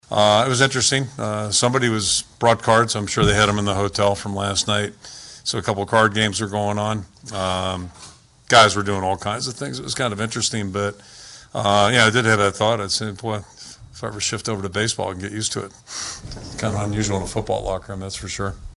Hawkeye coach Kirk Ferentz says the locker room looked like a baseball clubhouse during the more than four hours of delays.